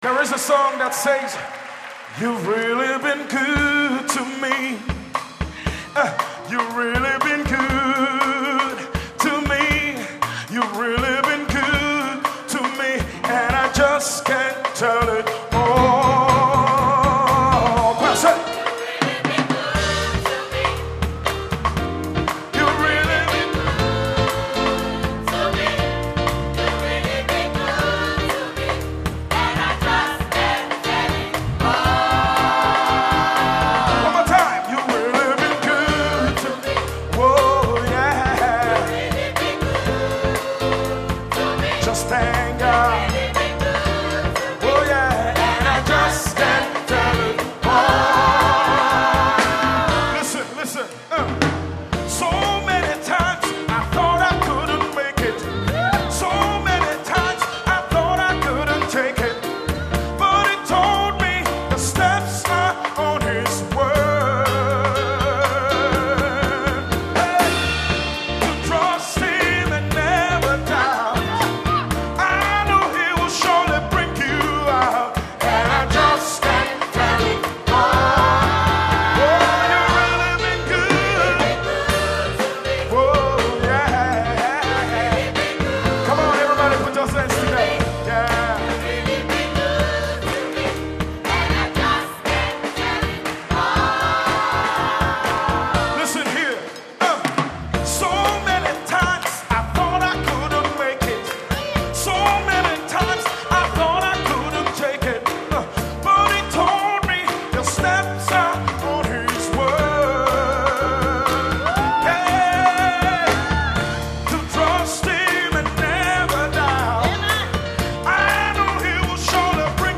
Irish-African Community Gospel Choir Competition 2007
The 2007 Irish-African Community Gospel Choir Competition held at St Finn Barre's Cathedral Cork City June 2007
Hosanna House Choir from Cavan